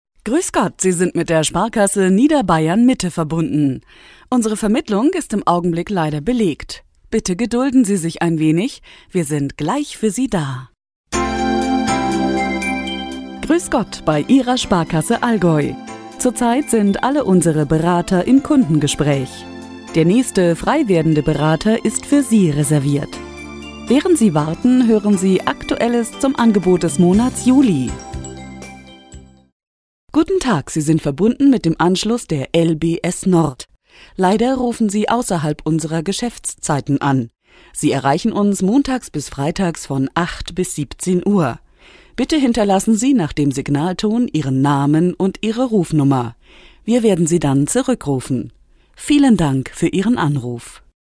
Sprechprobe: eLearning (Muttersprache):
Female german voices artist.